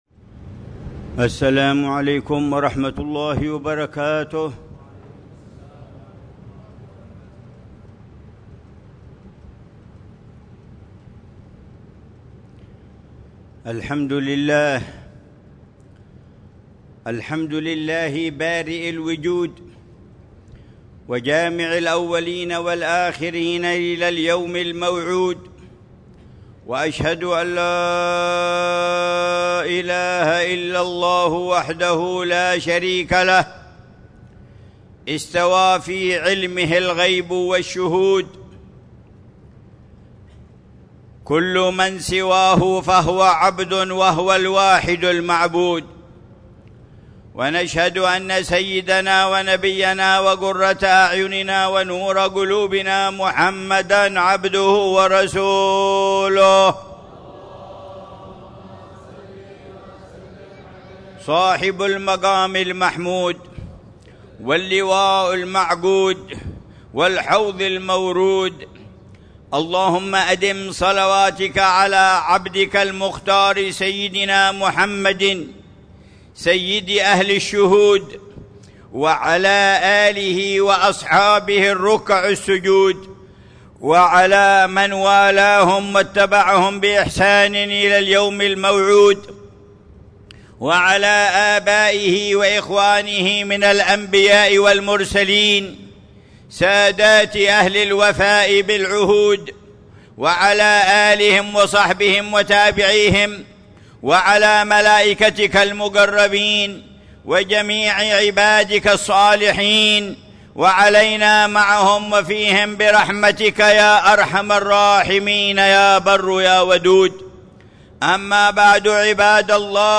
في خطبة الجمعة